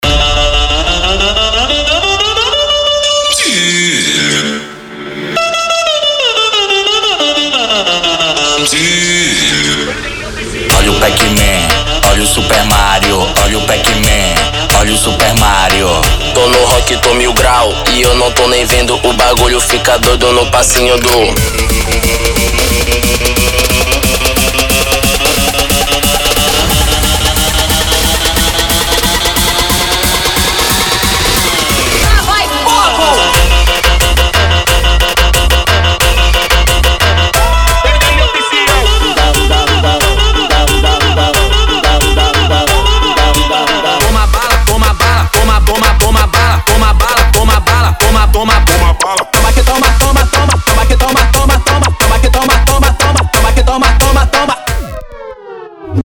Tecno Melody 2023